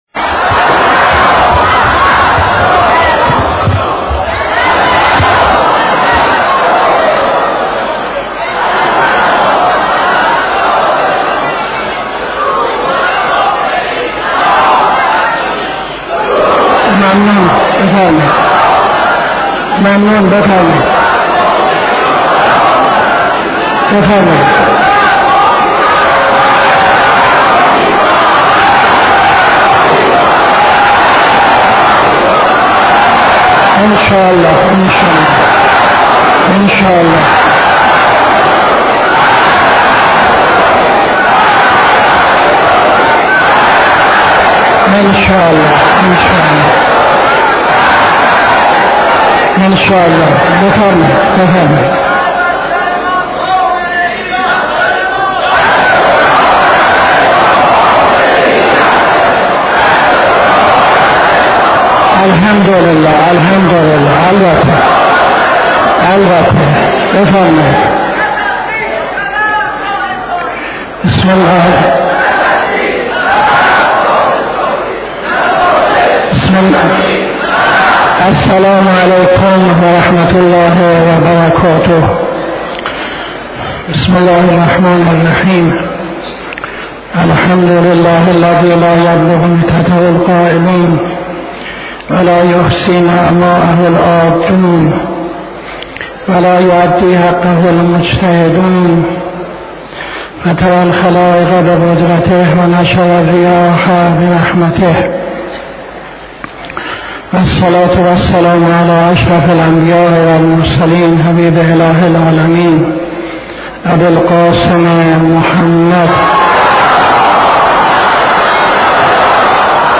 خطبه اول نماز جمعه 10-10-78